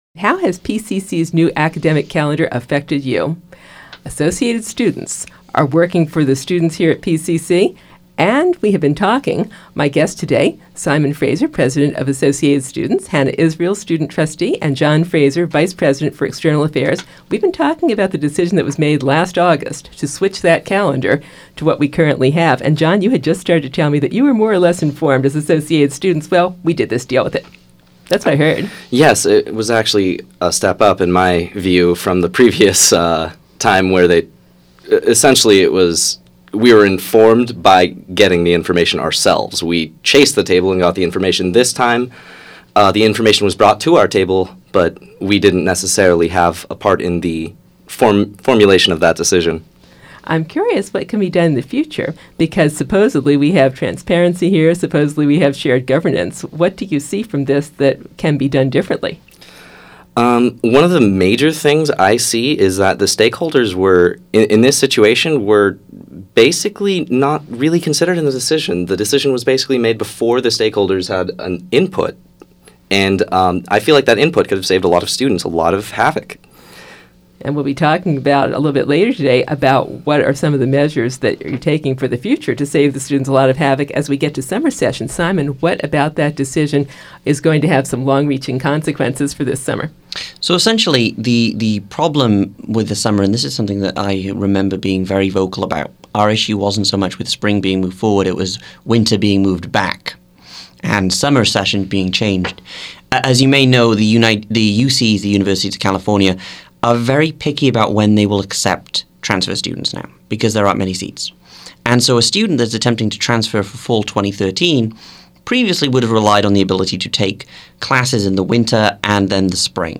Associated Students Interview, Part Two